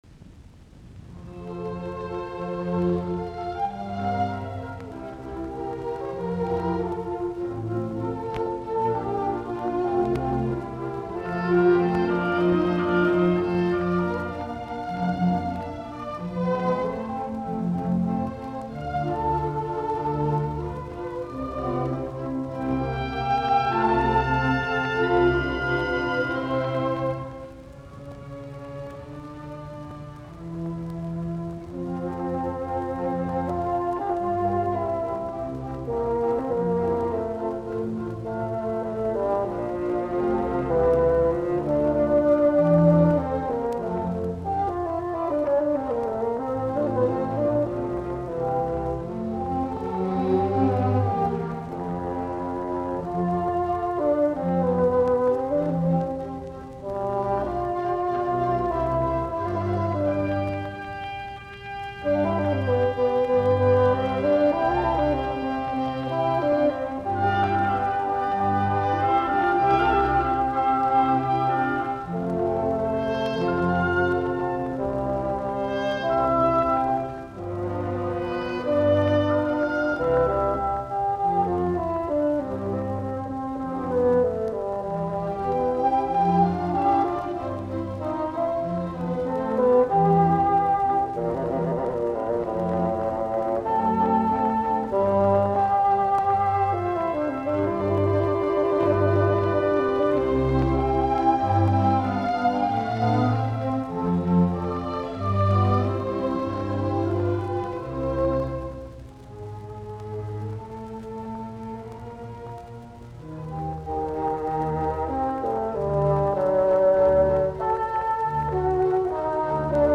musiikkiäänite
Soitinnus: Fagotti, ork.